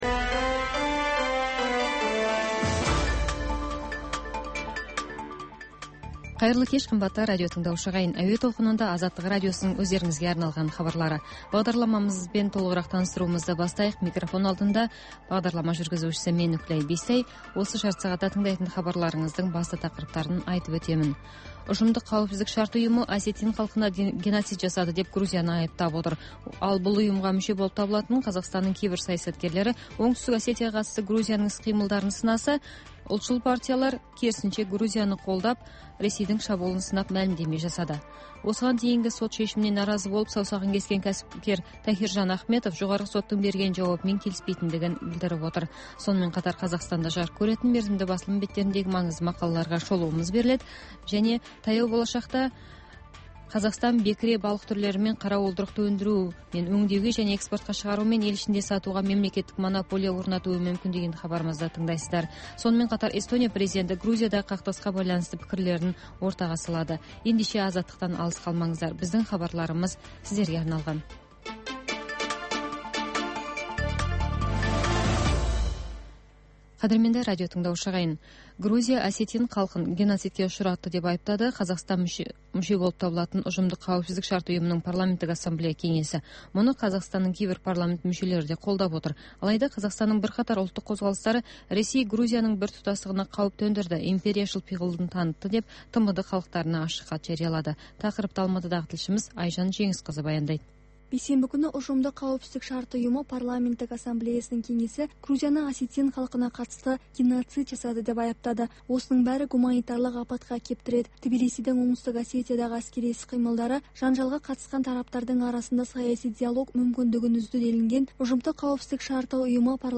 Бүгінгі күннің өзекті тақырыбына талқылаулар, пікірталас, оқиға ортасынан репортаж, сарапшылар талдауы мен қарапайым азаматтардың еркін пікірі, баспасөз шолуы, тыңдарман ойы.